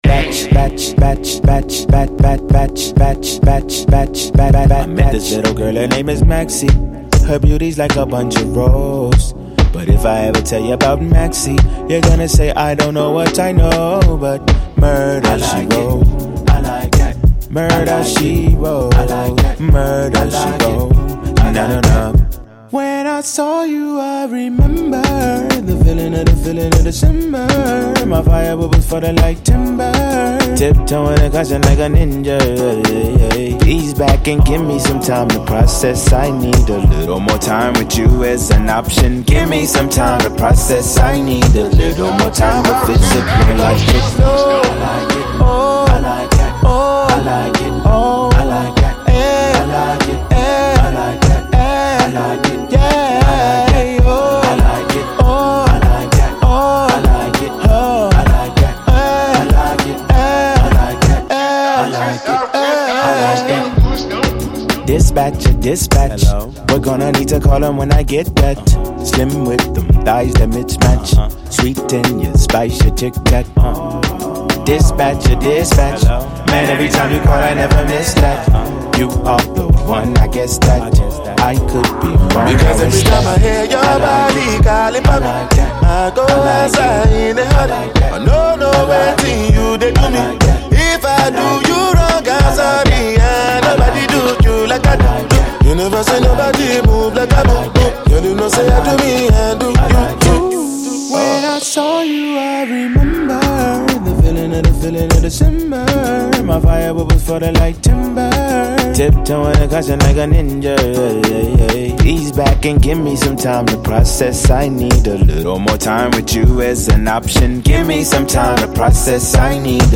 Alternative Rap
alternative, eclectic rap music
Afro-fusion